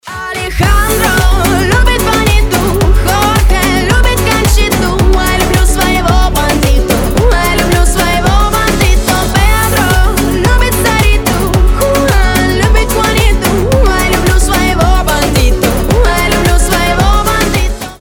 • Качество: 320, Stereo
гитара
зажигательные
Dance Pop